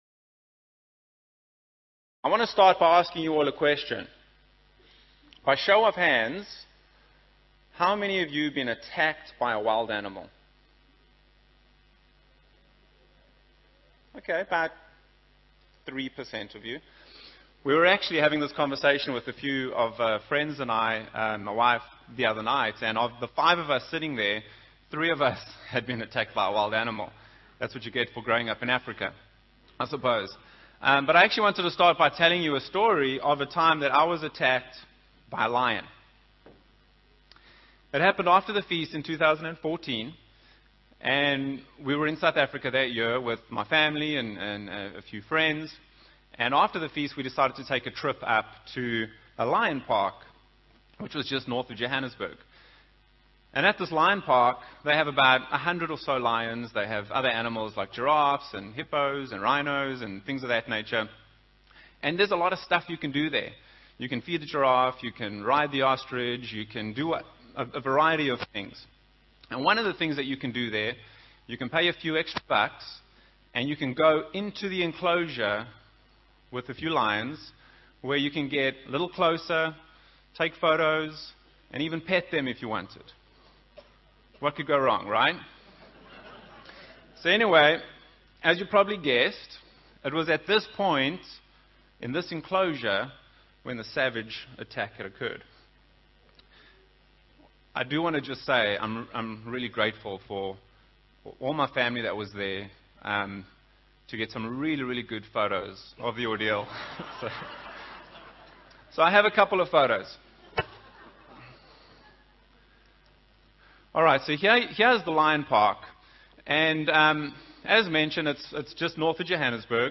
This sermon was given at the Montego Bay, Jamaica 2019 Feast site.